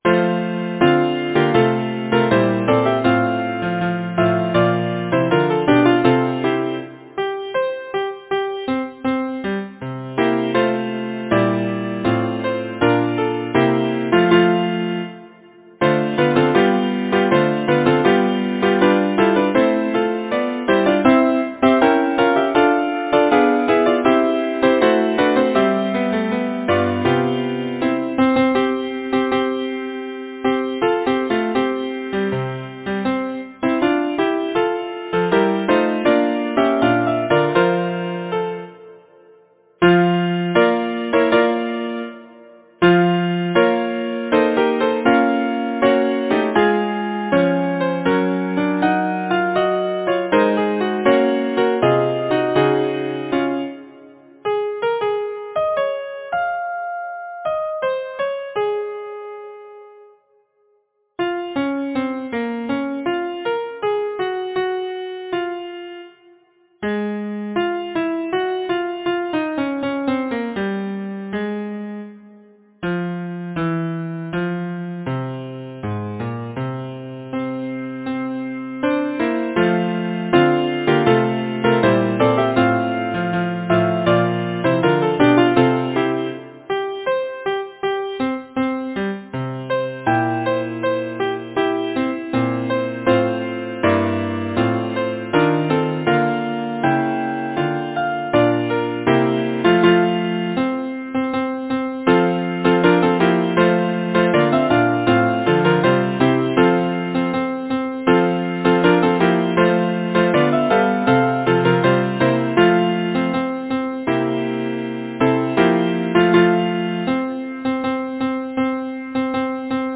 Title: Come, tuneful friends Composer: Charles Harford Lloyd Lyricist: Harold Boulton Number of voices: 4vv Voicing: SATB Genre: Secular, Partsong
Language: English Instruments: A cappella